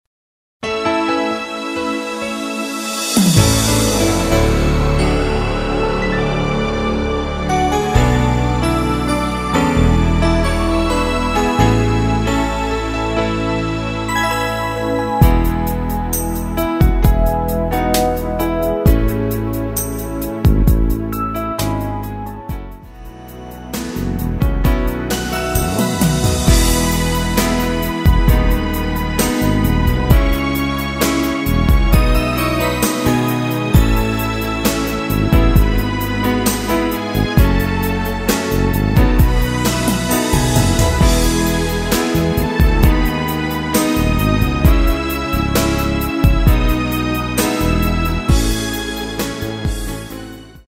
노래방에서 음정올림 내림 누른 숫자와 같습니다.
앞부분30초, 뒷부분30초씩 편집해서 올려 드리고 있습니다.